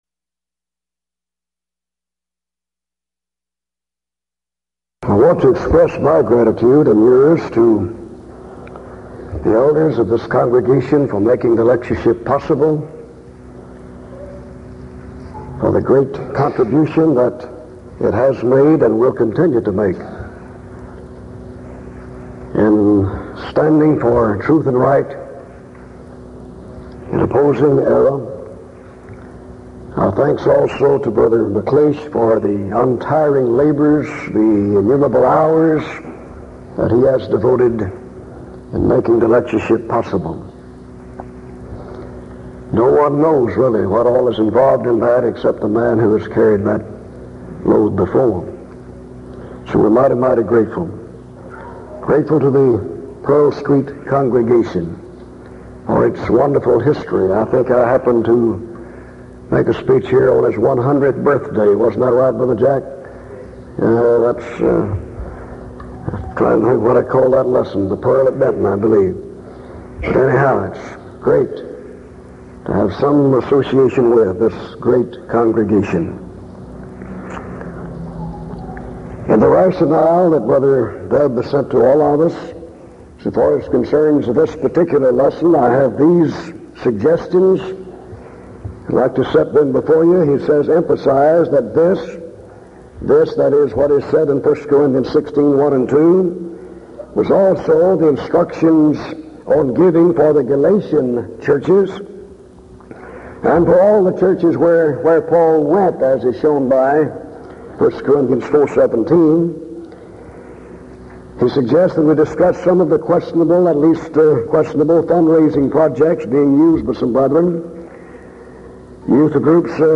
Event: 1982 Denton Lectures
lecture